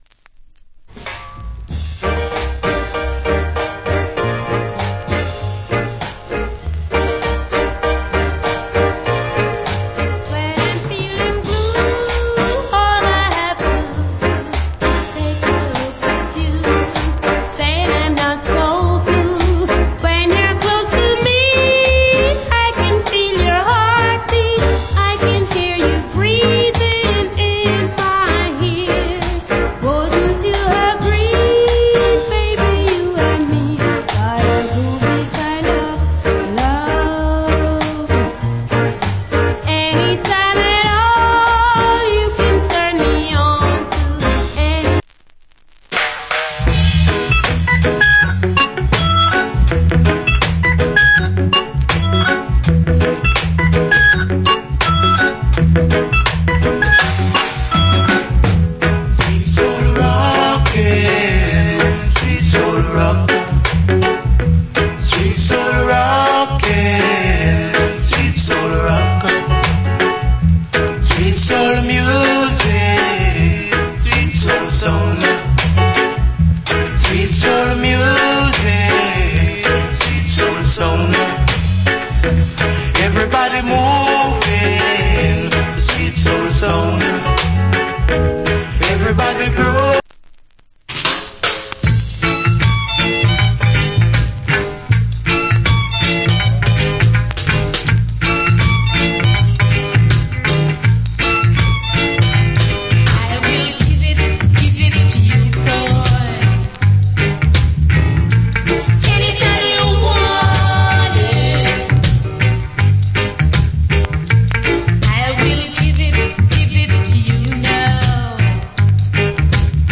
Great Rock Steady & Reggae